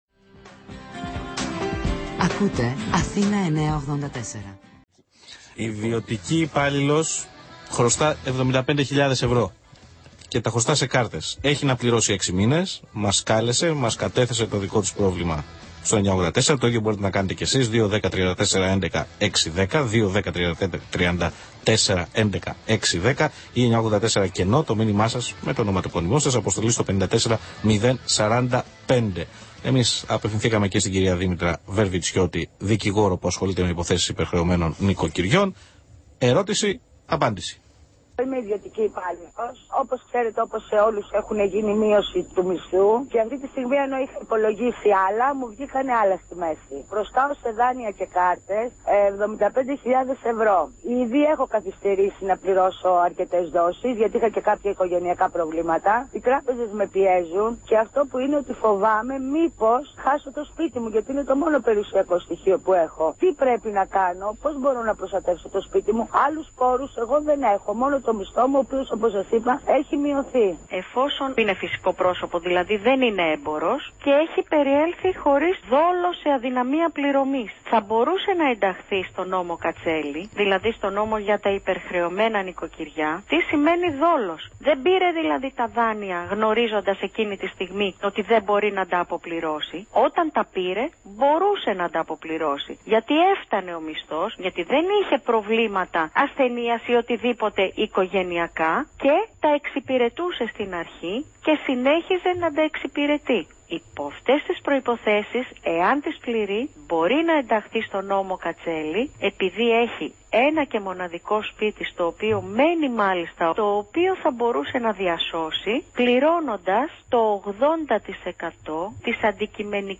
ραδιοφωνικές συνεντεύξεις
Για να ακούσετε απόσπασμα της συνέντευξης πατήστε εδώ